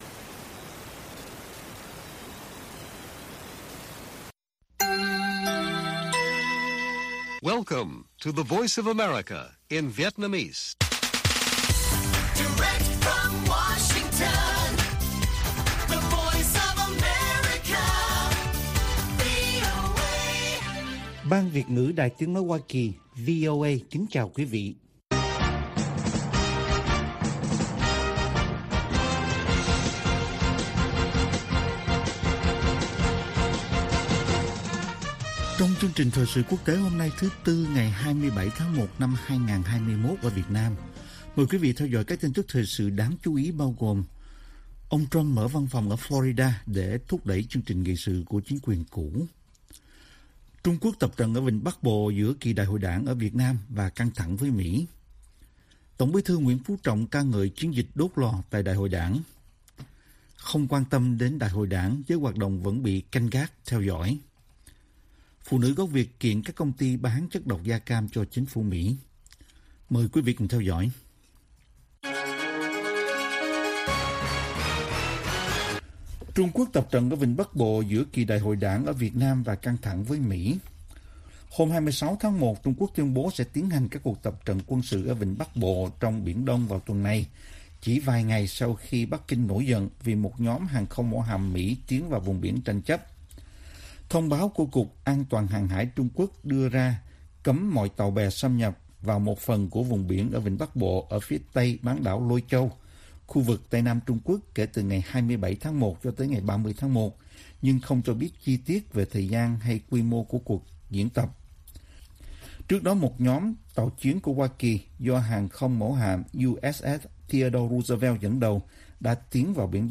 Bản tin VOA ngày 27/1/2021